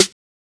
CardiakSnare 1.wav